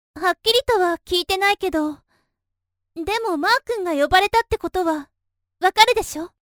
元気系キャラ。
ボイスサンプル@